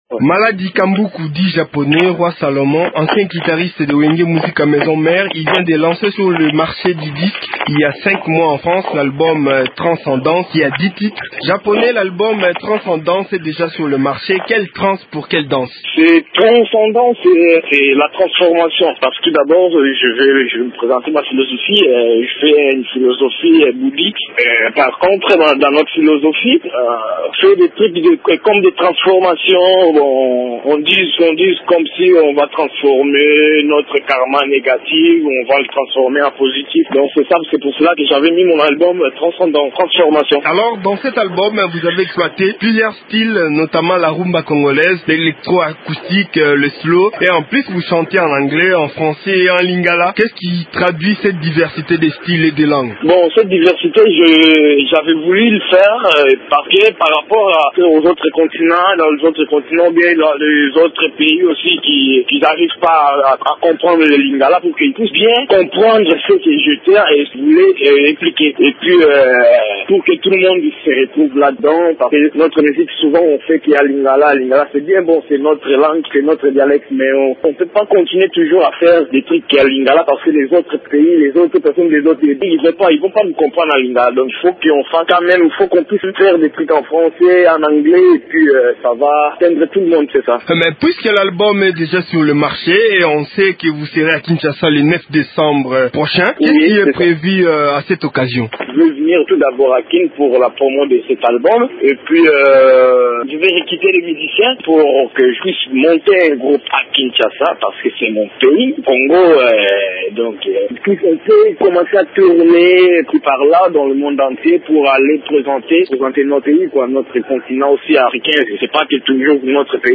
Joint au téléphone depuis Paris